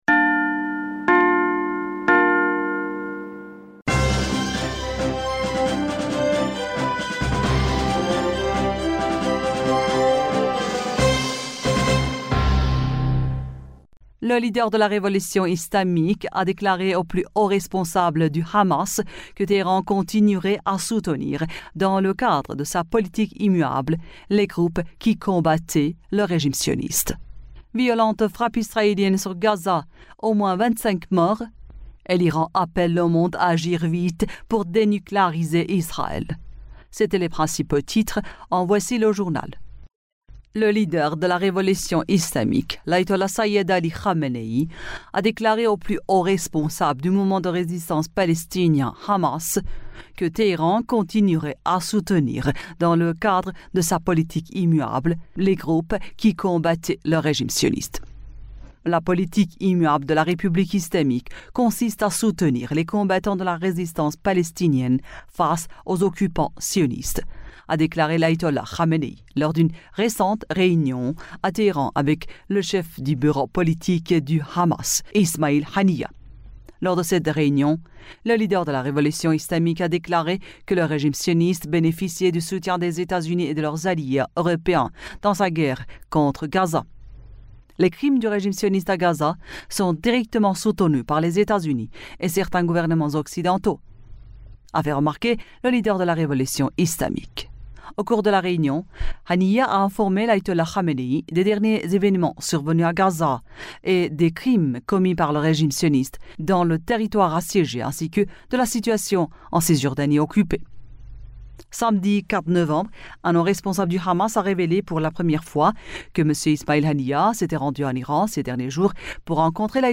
Bulletin d'information du 06 Novembre 2023